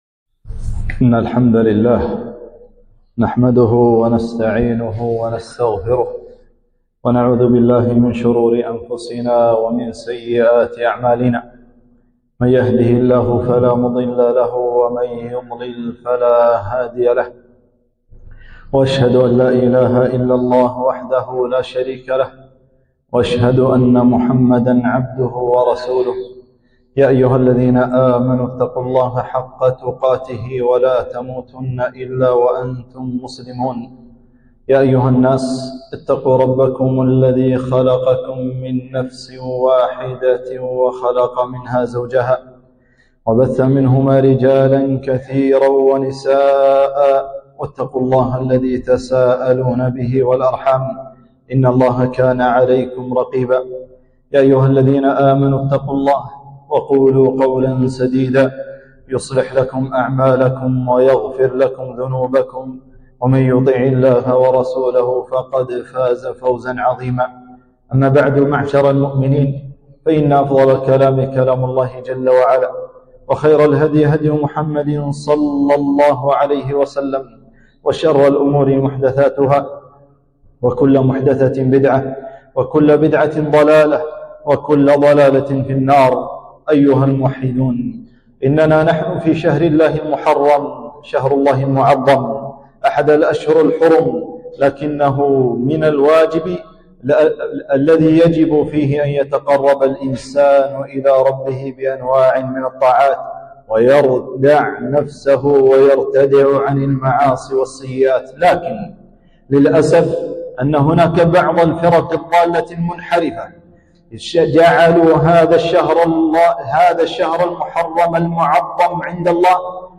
خطبة - فضل الصحابة وشهر محرم